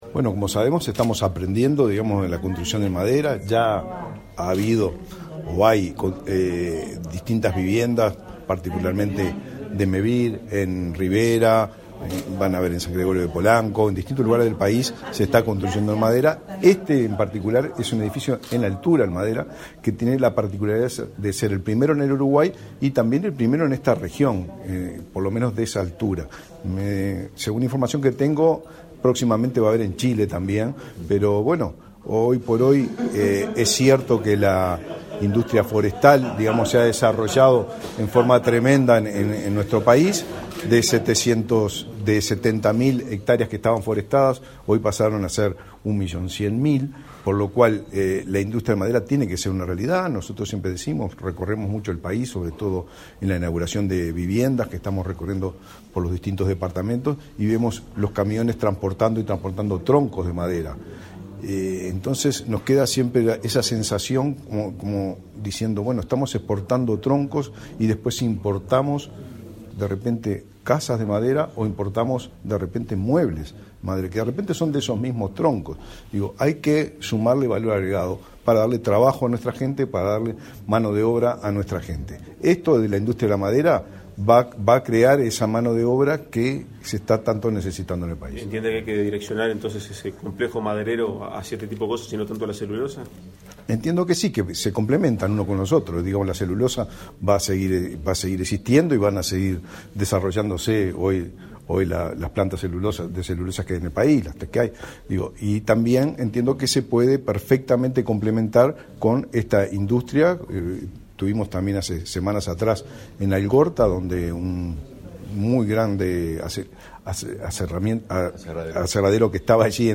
Declaraciones a la prensa del ministro del MVOT, Raúl Lozano
Tras el evento, el ministro Lozano realizó declaraciones a la prensa.